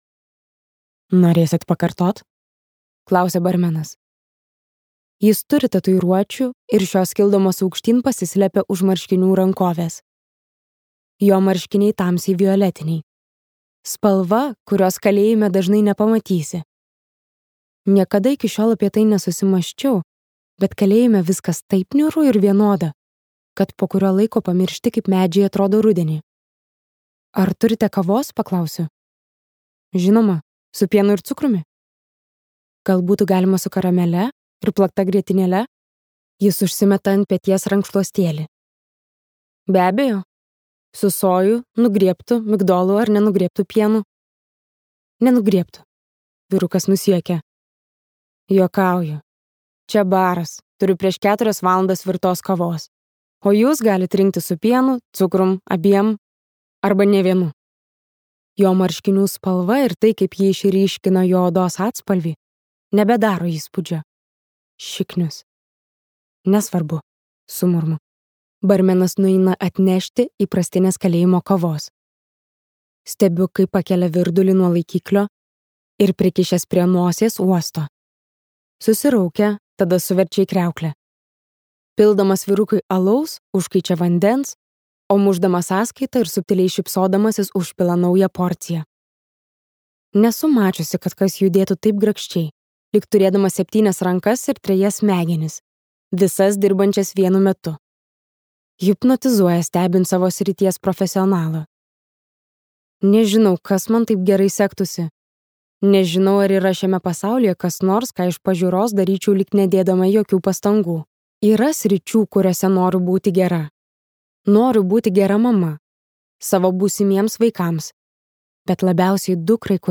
Colleen Hoover audioknyga